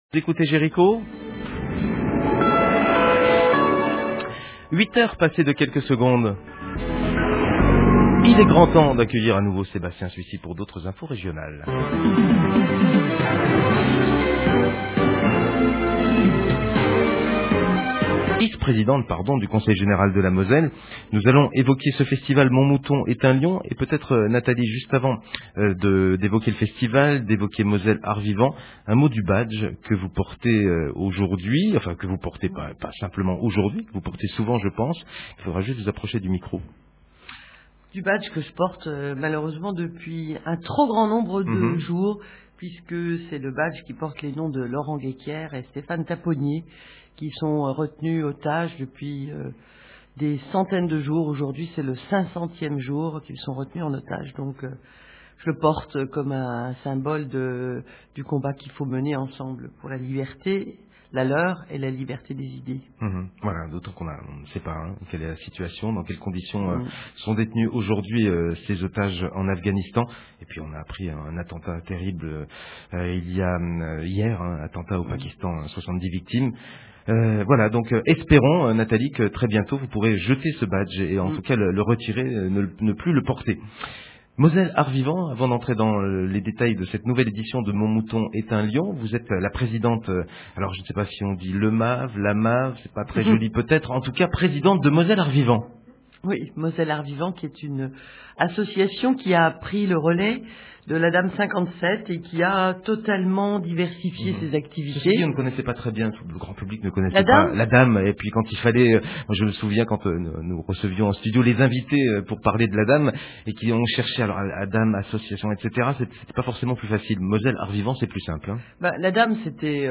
Nathalie Griesbeck était l’invitée de la Matinale de Radio Jérico la semaine dernière, à l’occasion du lancement du festival « Mon Mouton est un lion » qui a débuté le 11 mai dernier.